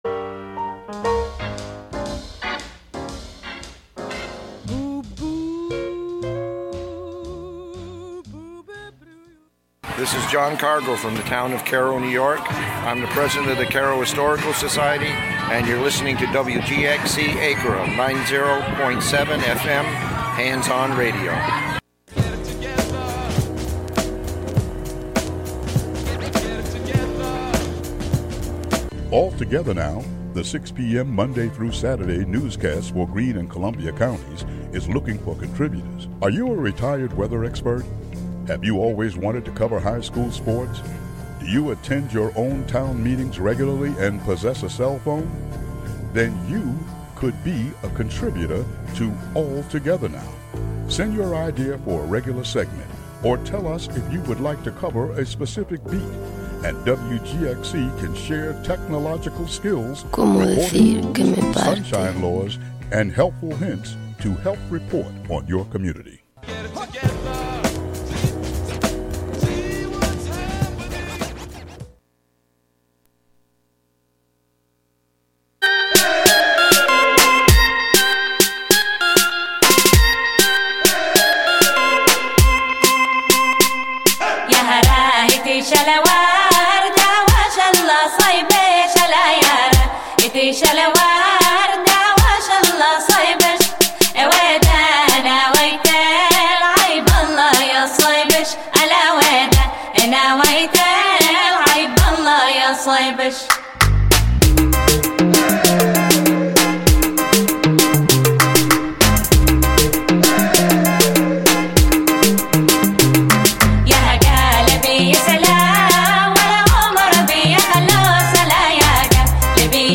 Youth Radio